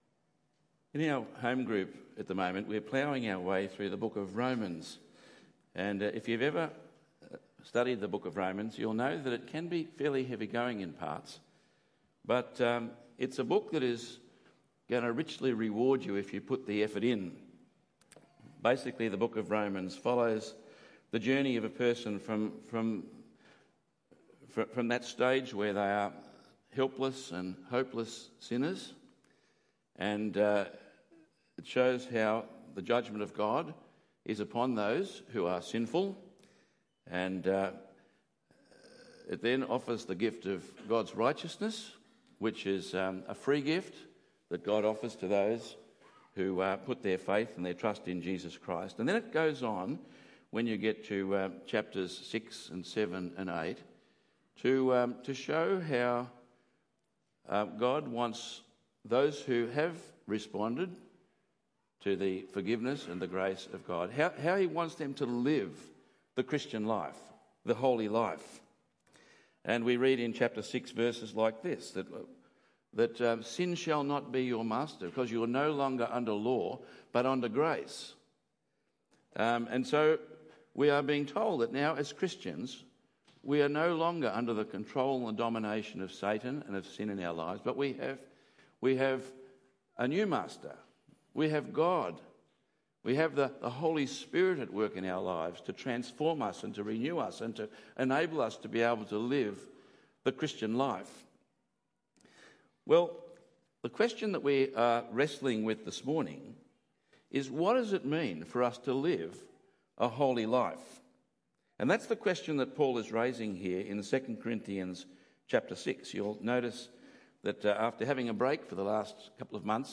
That may seem tough… but it’s necessary. 2 Corinthians 6:14-7:1 Tagged with Sunday Morning